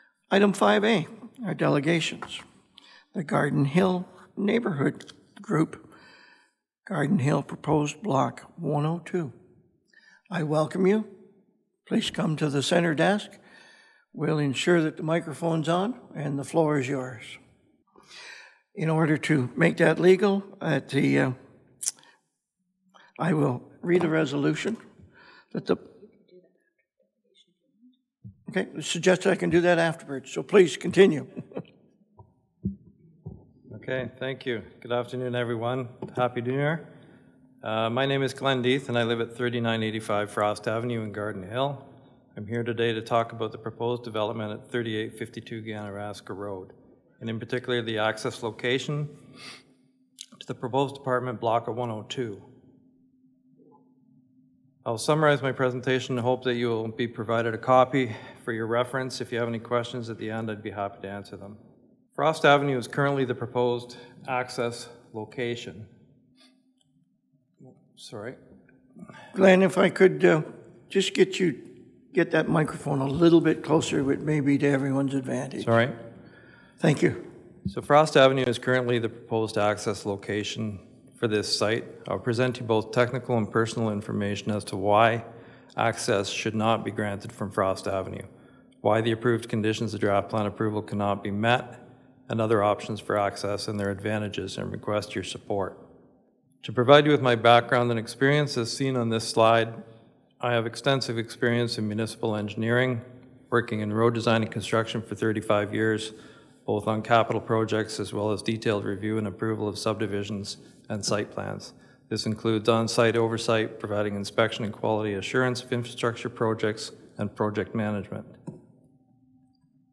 A Garden Hill resident highlighted multiple concerns about a proposed development at 3852 Ganaraska Road, including what he saw as negative environmental and community impacts, during a Northumberland County public works committee meeting on Jan. 5.